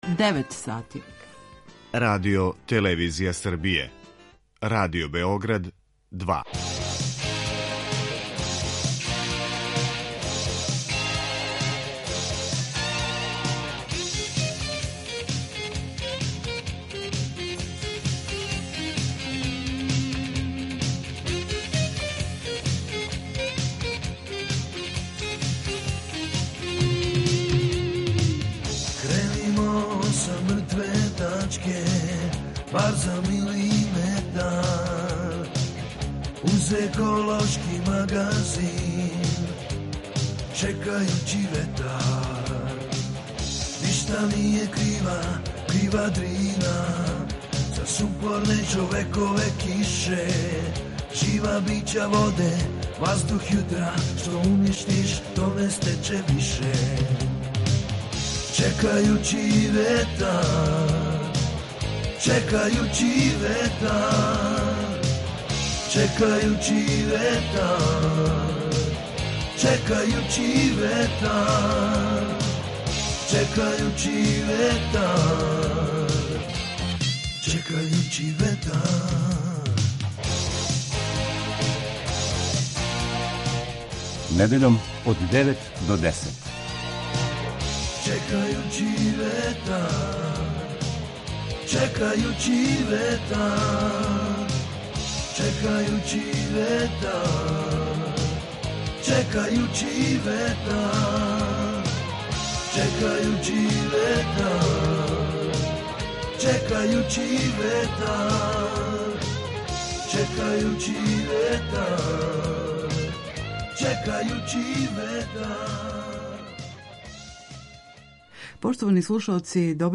Избор музике